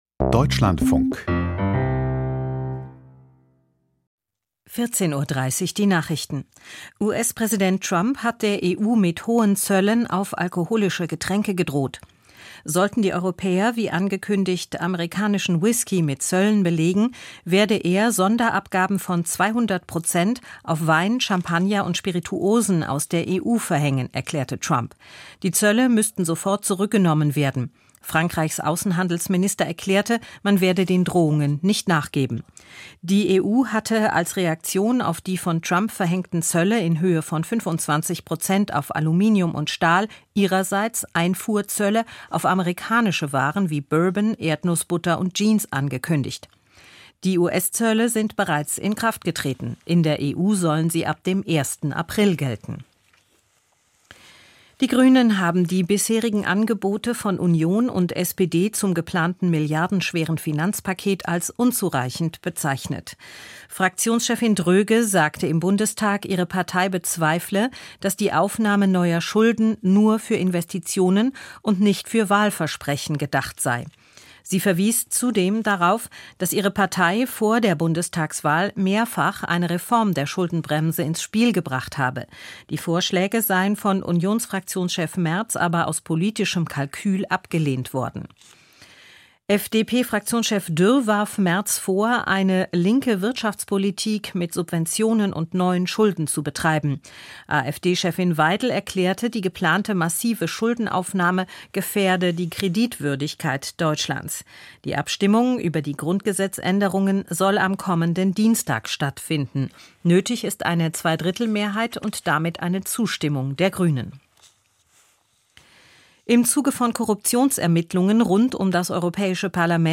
Die Deutschlandfunk-Nachrichten vom 13.03.2025, 14:30 Uhr